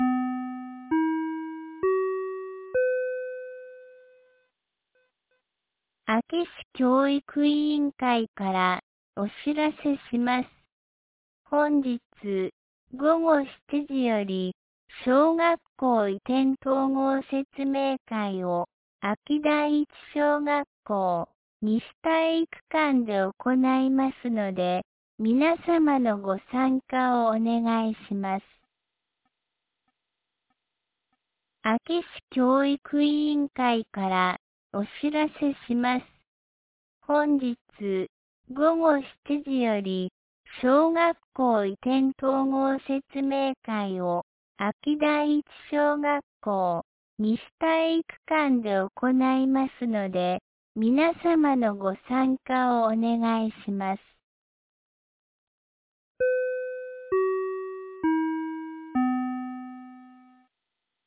2026年01月29日 17時21分に、安芸市より安芸へ放送がありました。